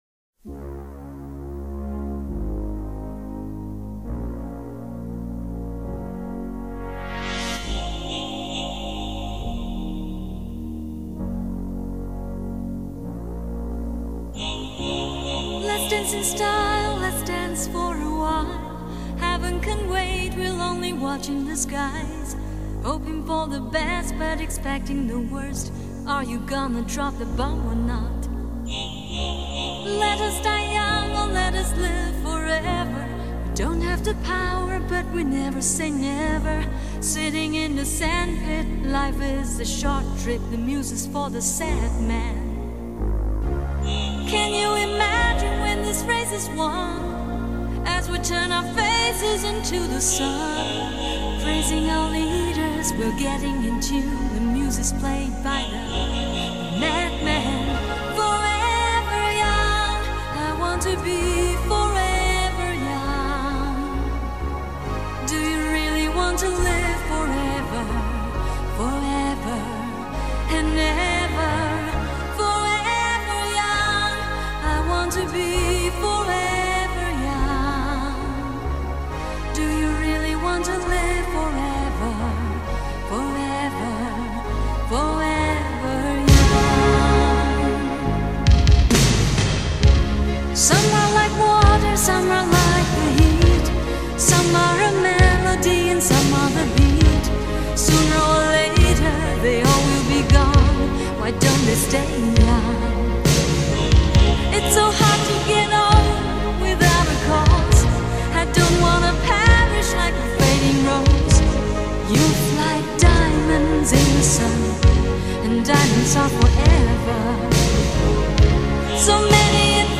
☆专业24bit混音母带处理，原音高品质重现
优雅的英文咬字，伴随张力十足的情感拿捏与澎湃感人的唱功，总是让人无法自拔地如痴如醉。
听见天使般的歌声